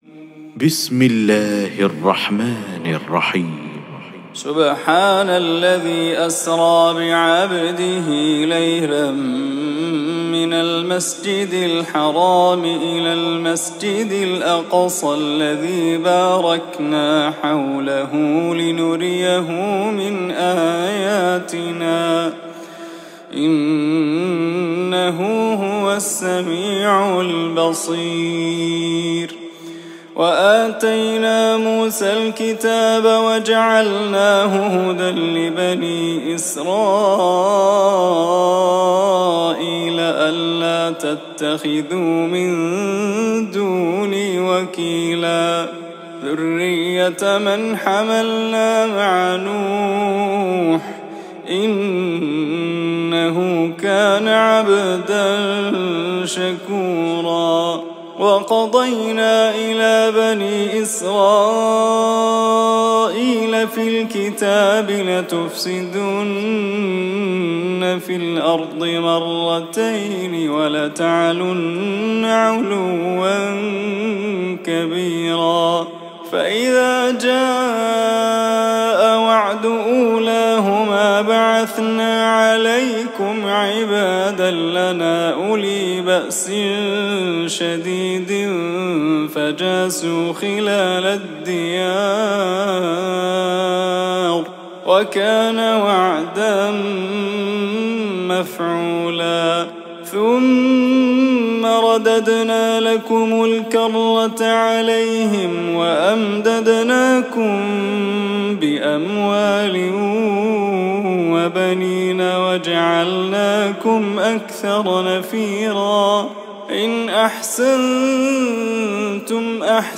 تلاوة عطرة خاشعة من سورة الإسراء